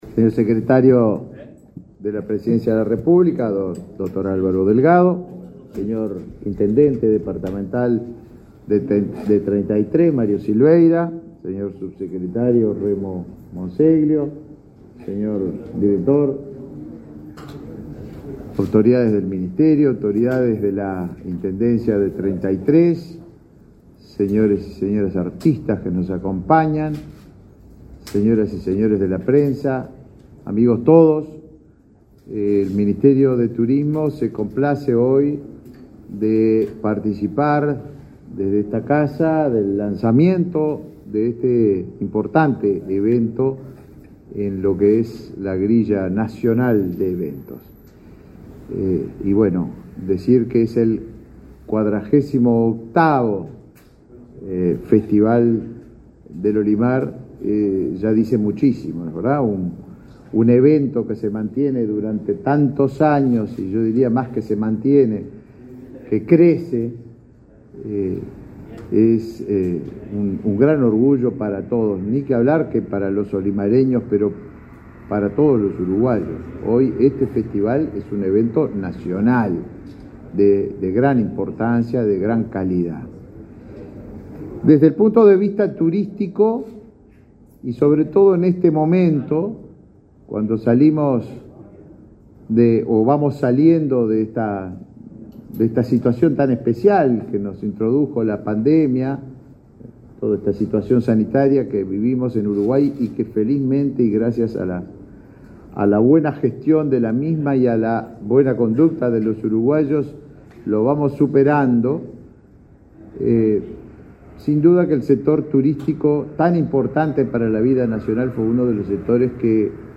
Conferencia de prensa por el lanzamiento de la 48.ª Edición del Festival del Olimar